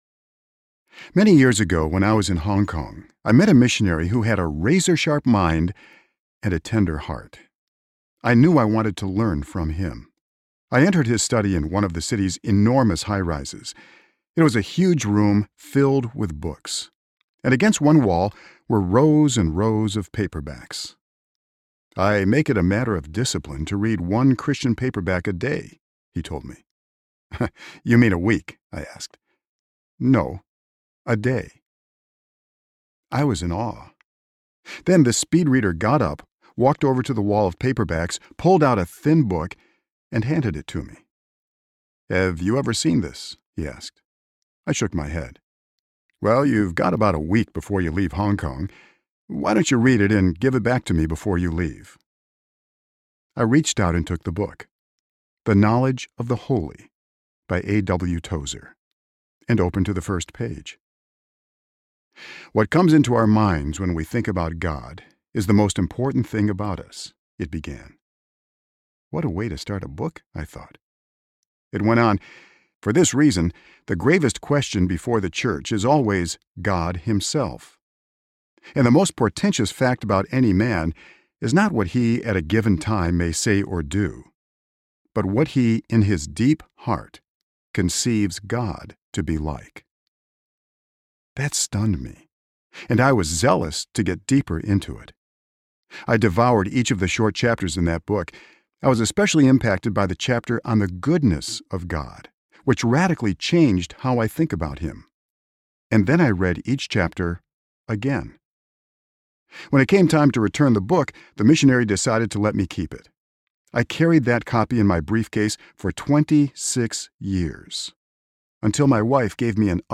Good to Great in God’s Eyes Audiobook
Narrator
9.15 Hrs. – Unabridged